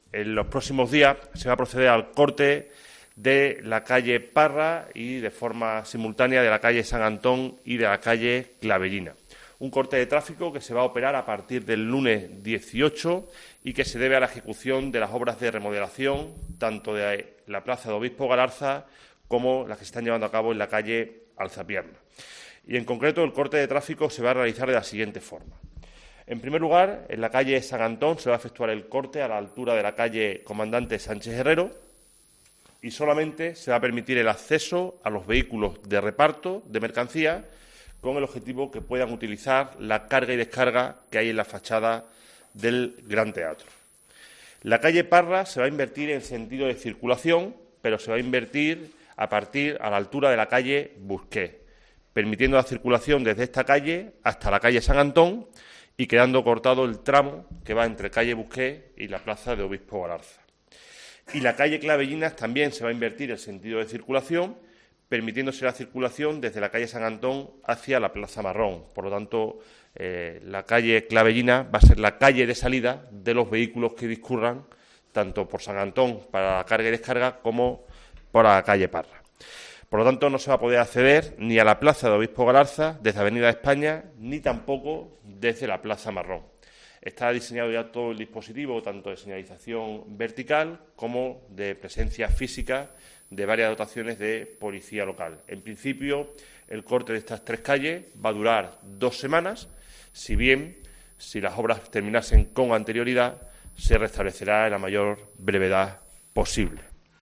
El portavoz del gobierno, Rafael Mateos, informa sobre el corte de calle Parras, San Antón y Clavellinas